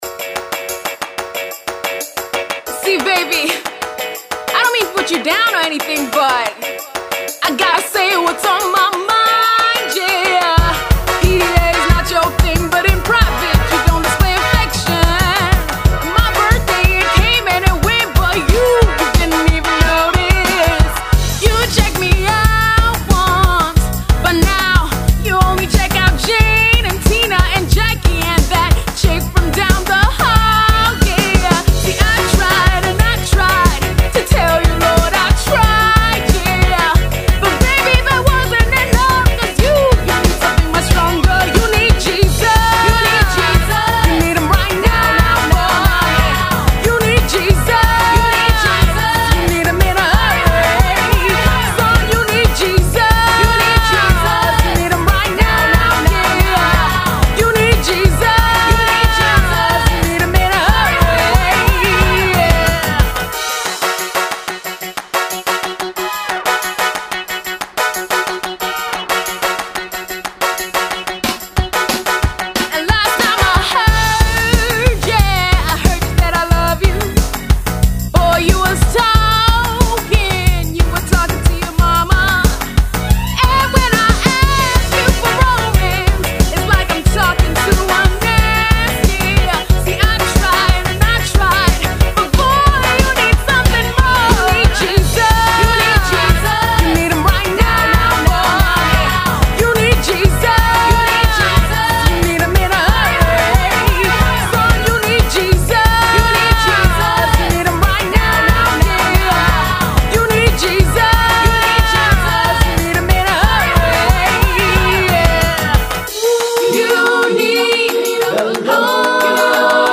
with her contemporary Jazz meets Afro Pop and Dance/Pop